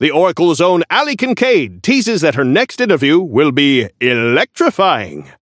Newscaster voice line - The Oracle's own Allie Kincaid teases that her next interview will be... electrifying!
Newscaster_seasonal_headline_16.mp3